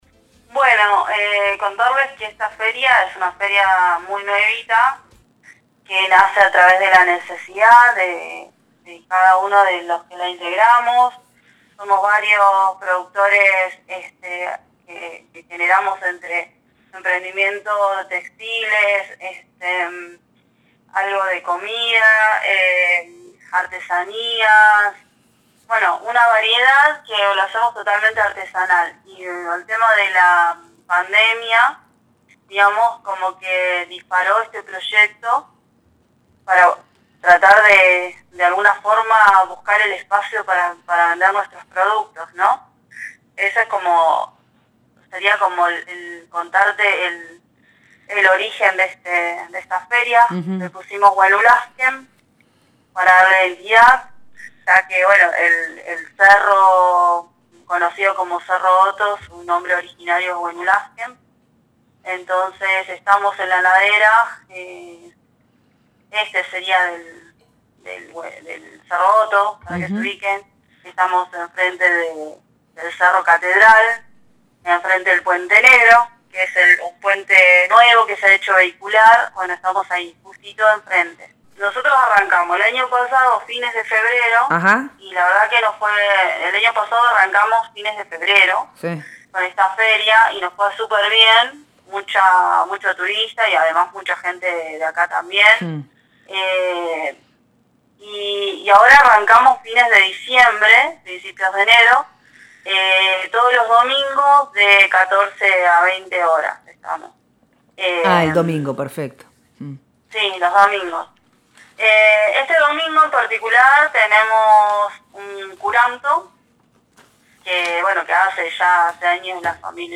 dialogó con Nosotres les Otres sobre la feria de productores de los domingos en la comunidad, y destacó el Curanto que se suma el fin de semana. Por otra parte, compartió su mirada sobre la temporada de verano, el cuidado del medio ambiente y el trabajo junto a la Junta Vecinal de Villa Los Coihues para resguardar la zona conocida como “la pampita”porque es territorio comunitario mapuche.